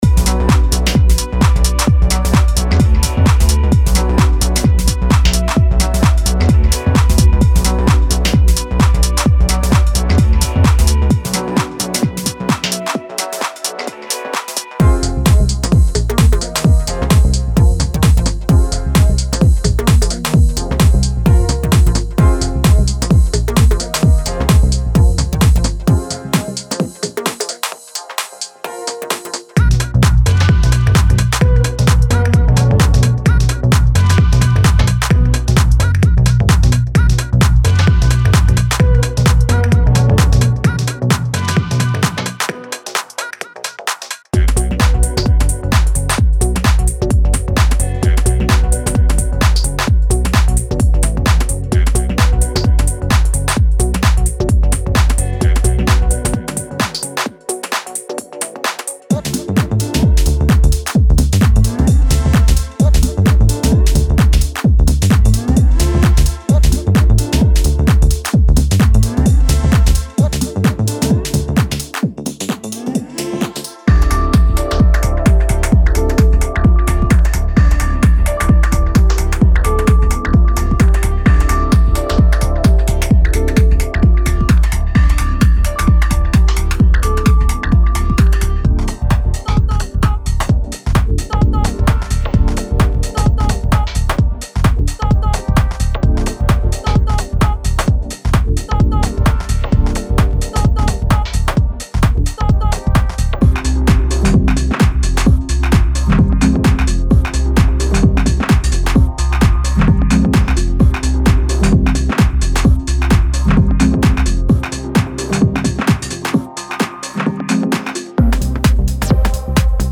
Genre:Tech House
130BPMおよび133BPMに分かれた581ループ
デモサウンドはコチラ↓